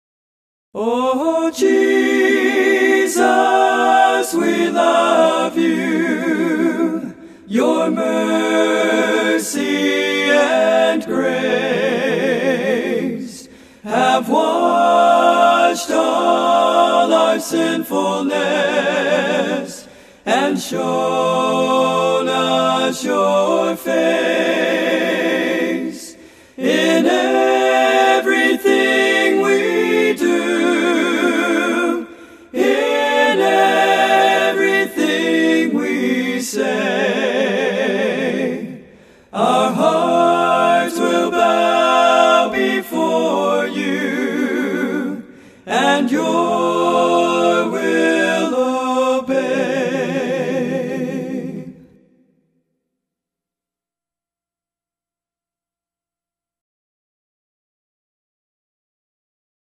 I wrote this for our church choir to do as a call to worship because I was tired of the ones we were using at the time.
WOW! Love the harmonies.